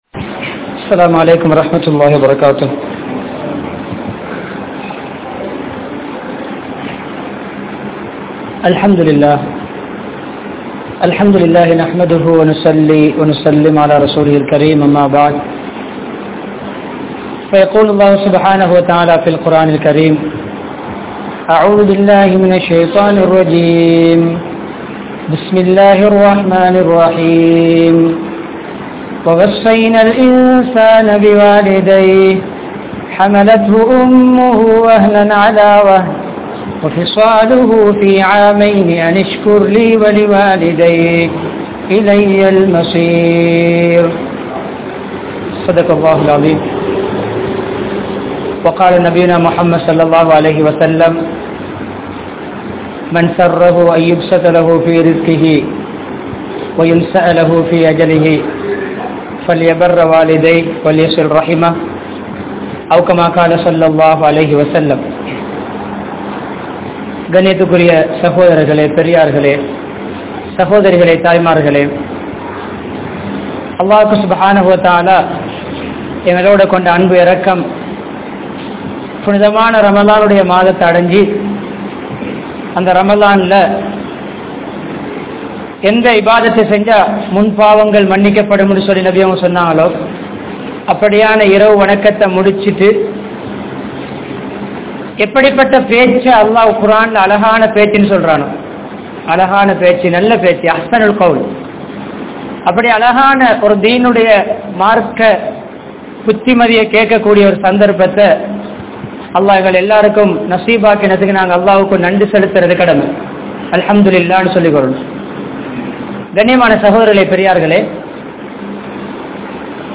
Ramalaanum Petroarhalum (ரமழானும் பெற்றோர்களும்) | Audio Bayans | All Ceylon Muslim Youth Community | Addalaichenai
Faluliyyah Masjidh